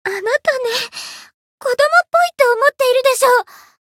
灵魂潮汐-爱莉莎-互动-不耐烦的反馈1.ogg